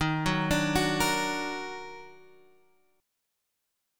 D# Minor Major 7th
D#mM7 chord {x x 1 3 3 2} chord